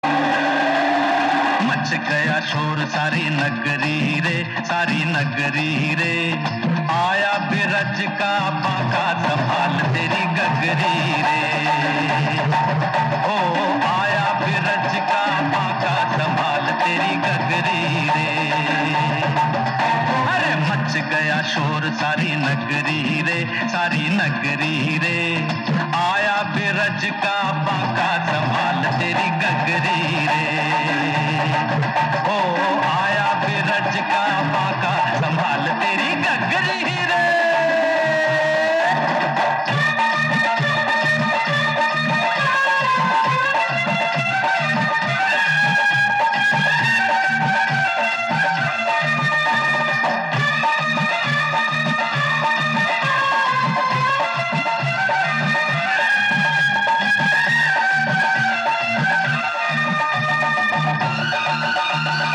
Categories Indian Festival Ringtones